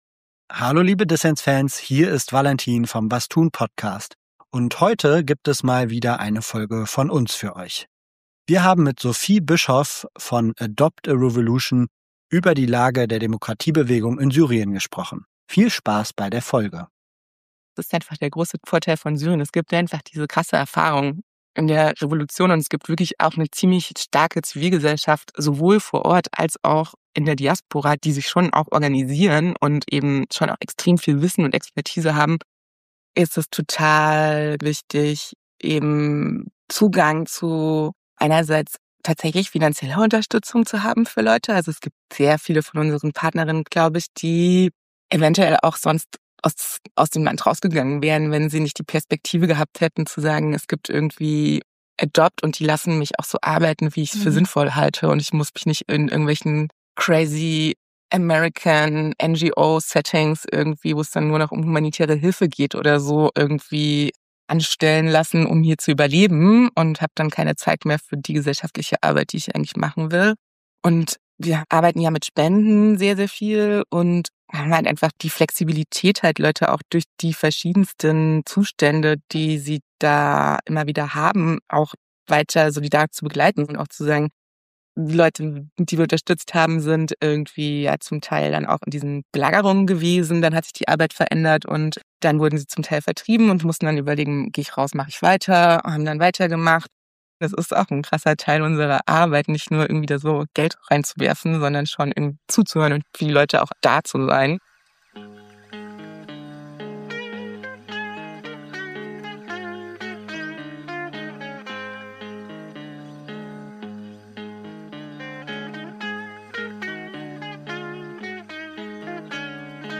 Das Interview wurde am 13.2. aufgezeichnet.